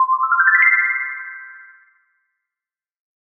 Звуки облака, мыслей
Детская версия